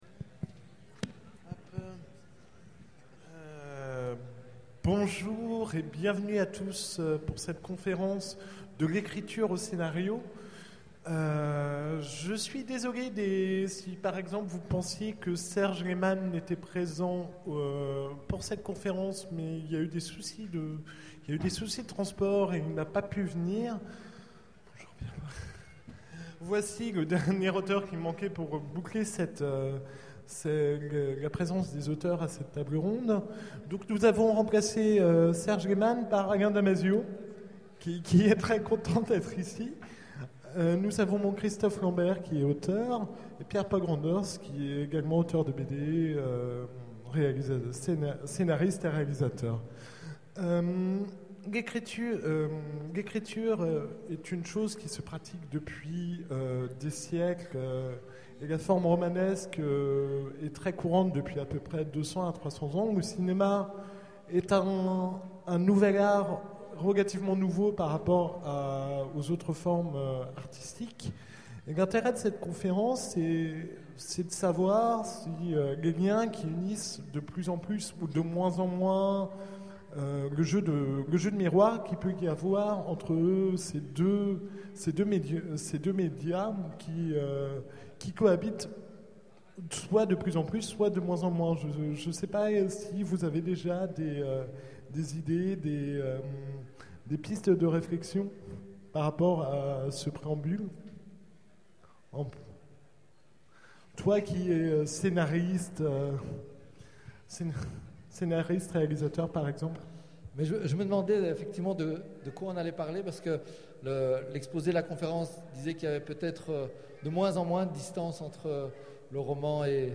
Utopiales 12 : Conférence De l'écriture au scénario
Conférence